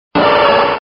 Archivo:Grito de Slowbro.ogg